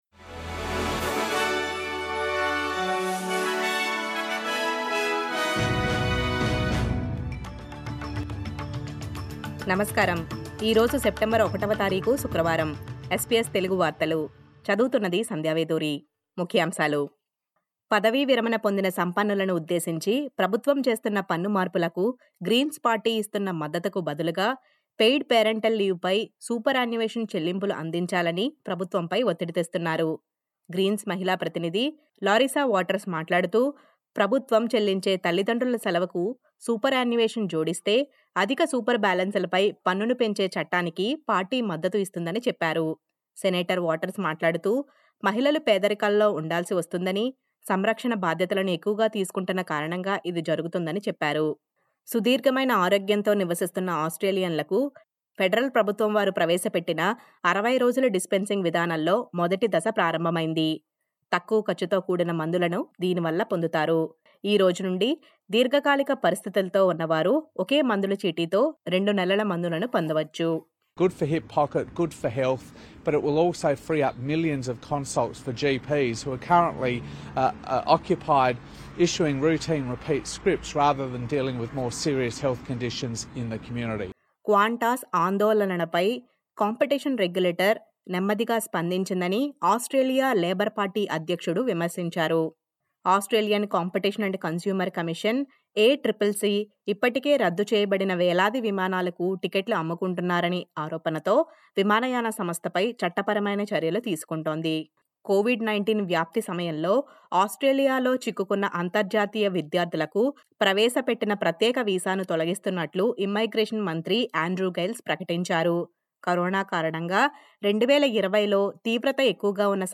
SBS Telugu వార్తలు.